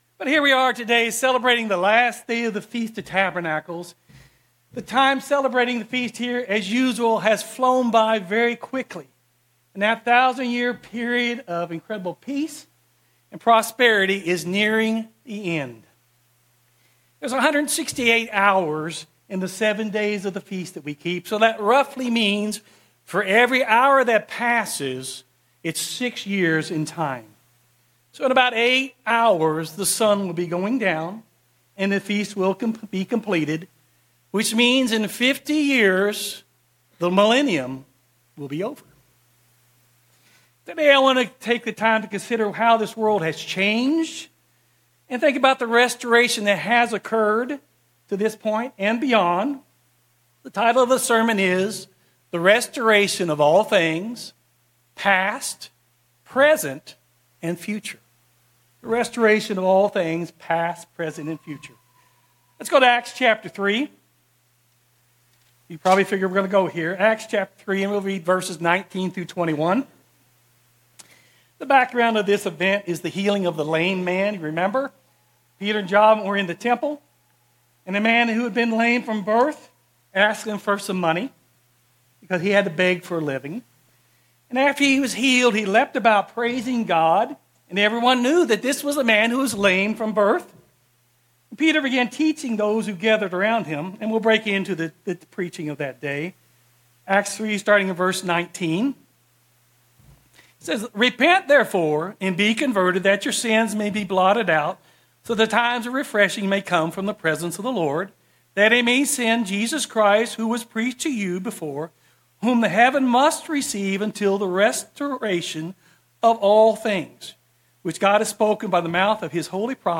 Given in Daytona Beach, Florida